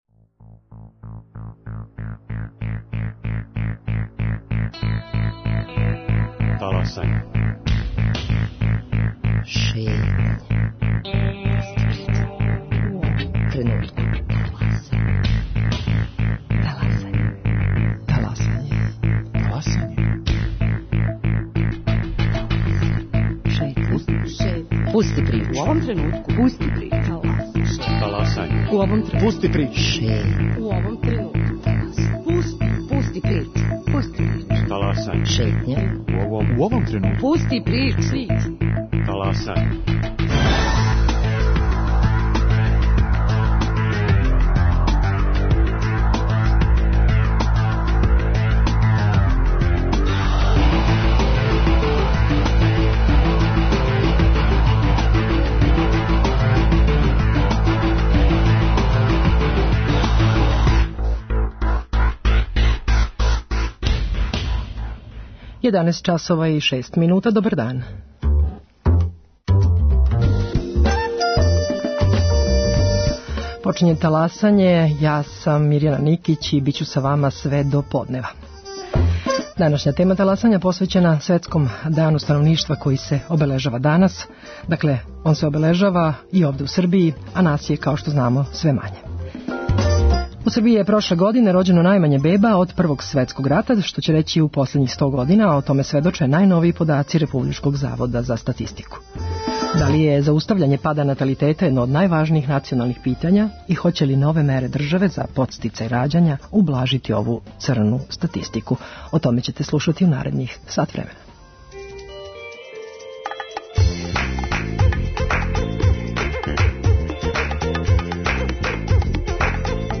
Гости: Славица Ђукић Дејановић, министарка без портфеља задужена за демографију и популациону политику